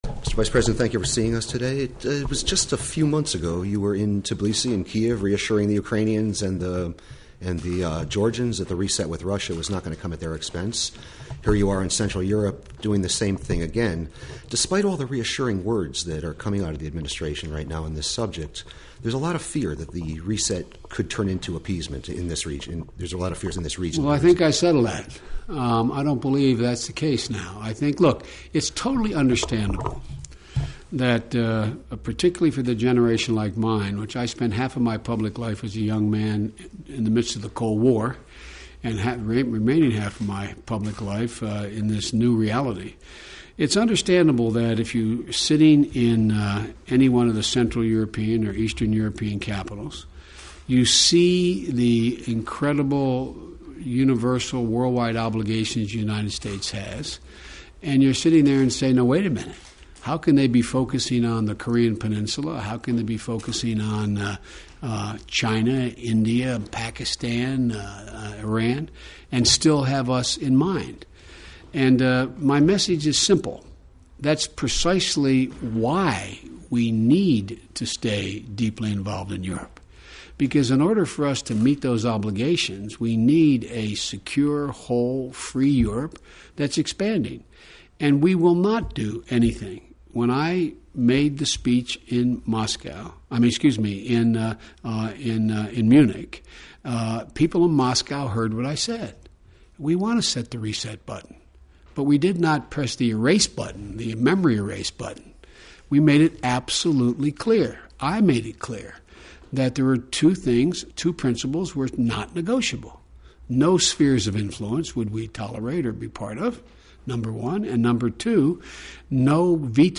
Full Interview: U.S. Vice President Joe Biden